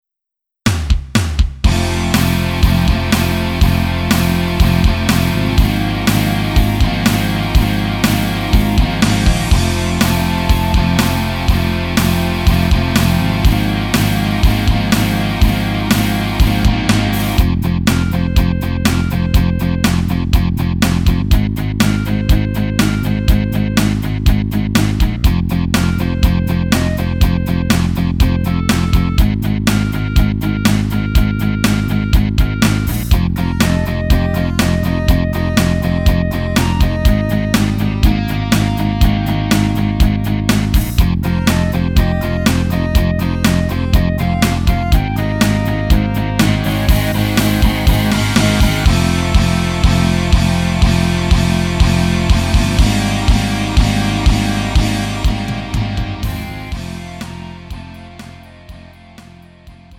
음정 -1키 3:32
장르 가요 구분